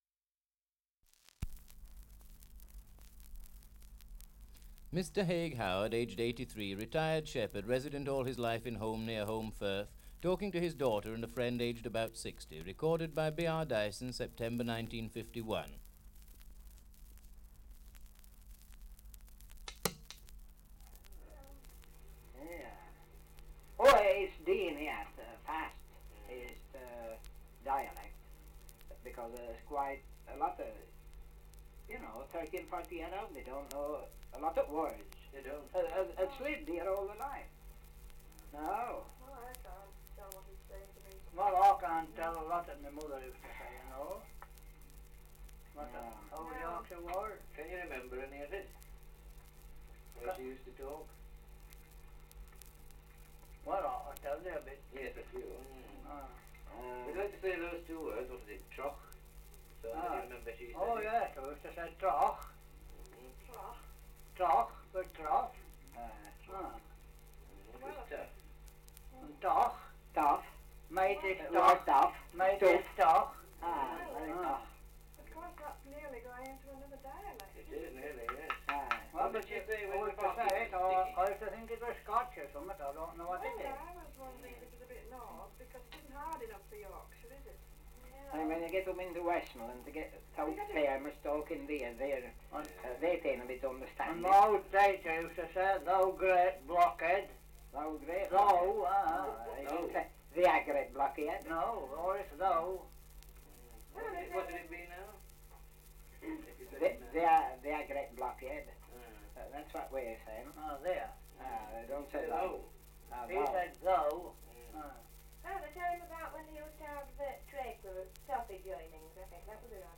Title: Survey of English Dialects recording in Holmbridge, Yorkshire
78 r.p.m., cellulose nitrate on aluminium